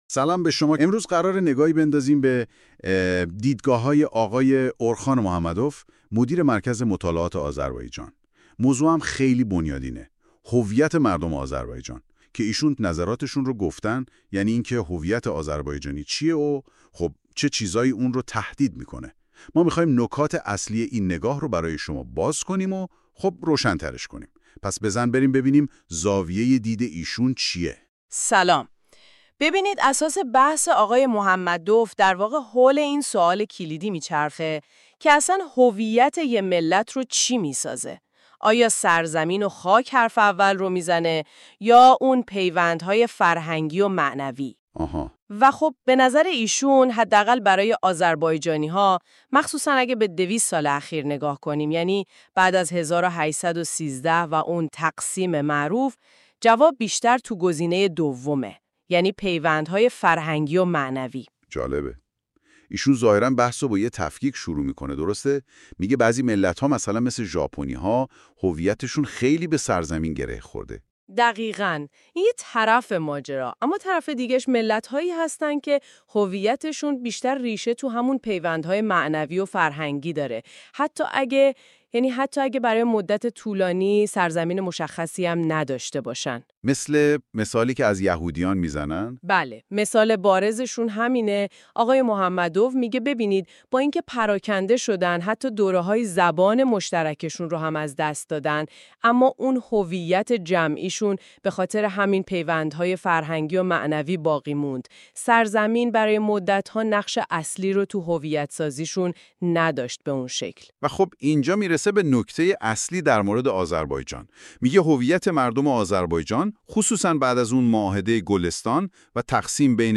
در گویندگی این برنامه از هوش مصنوعی استفاده شده و پیشاپیش به‌خاطر برخی اشتباهات اعرابی در تلفظ‌ها عذرخواهی می‌کنیم.